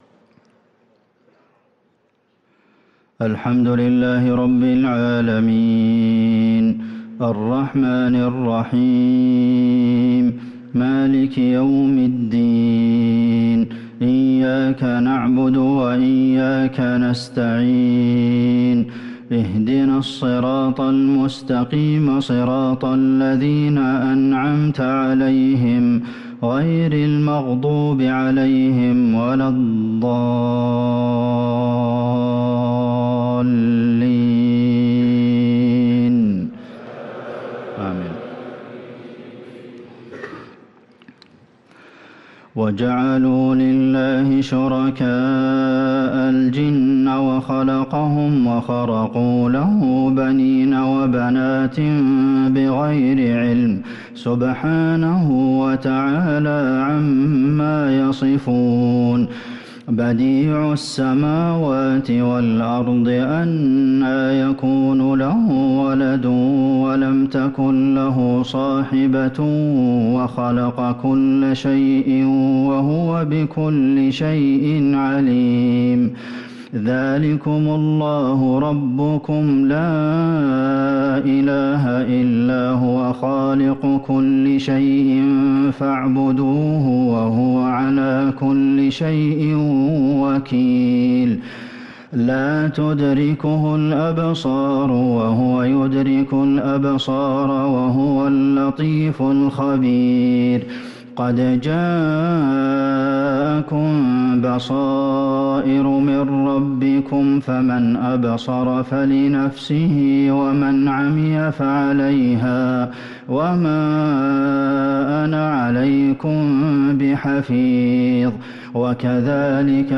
صلاة المغرب للقارئ عبدالله البعيجان 7 جمادي الأول 1445 هـ
تِلَاوَات الْحَرَمَيْن .